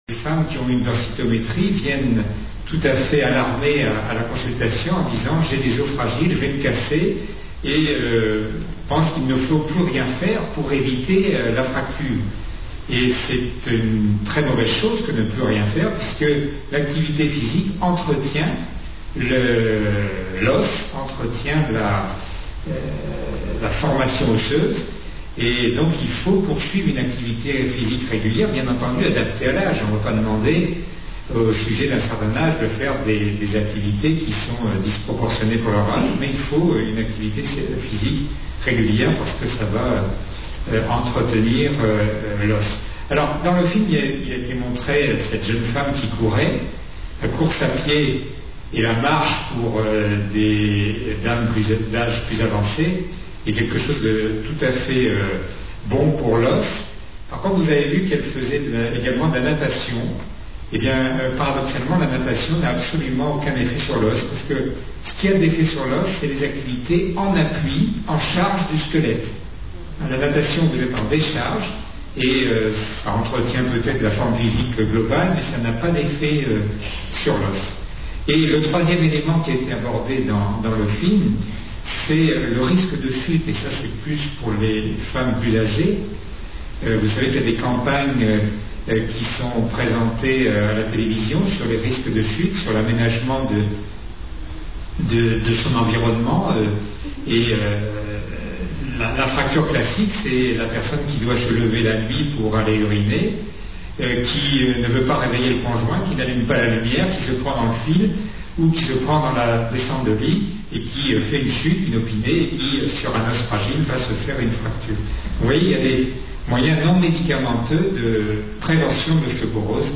Journée Santé et Bien-être des Seniors - Novembre 2005 - Nîmes
Conférence sur l'ostéoporose